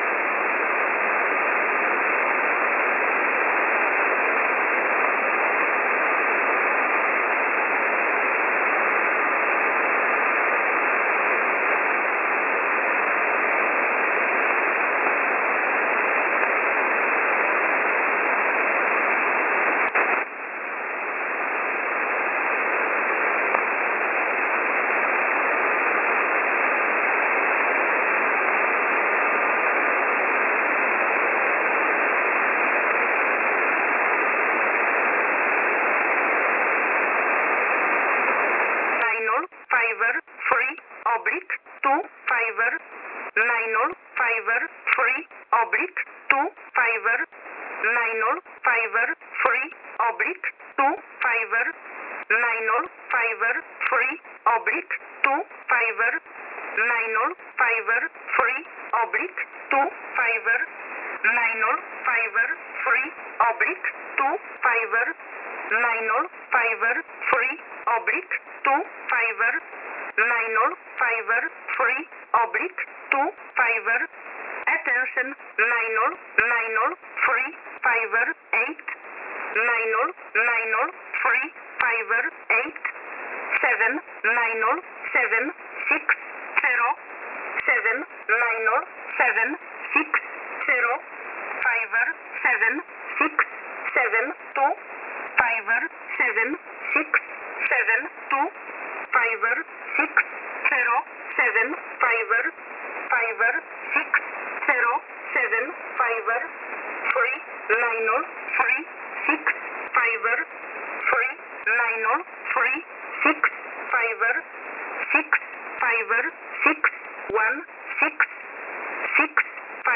Mode: USB
(short pause)